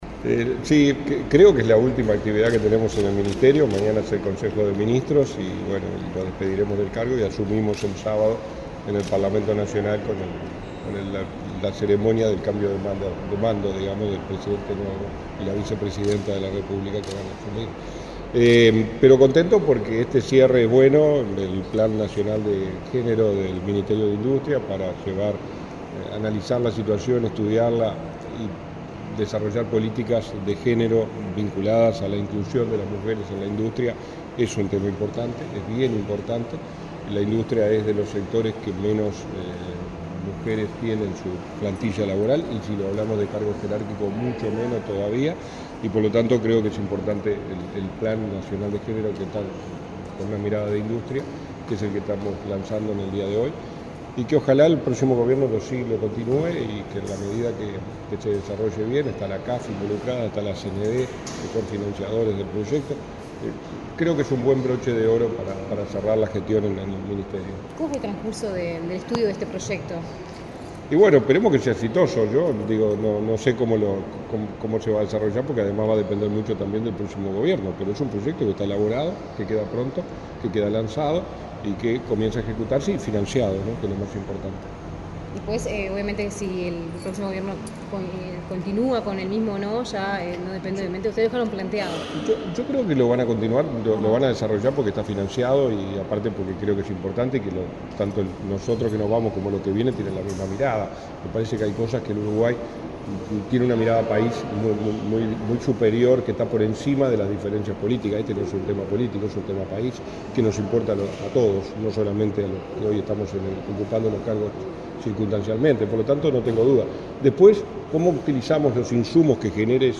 Declaraciones del subsecretario de Industria, Walter Verri
Declaraciones del subsecretario de Industria, Walter Verri 27/02/2025 Compartir Facebook X Copiar enlace WhatsApp LinkedIn Este jueves 27 en la Torre Ejecutiva, el subsecretario de Industria, Walter Verri, participó en la presentación del Plan Nacional de Género para el Desarrollo Industrial.